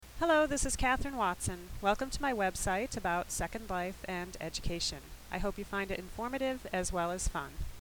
greeting.mp3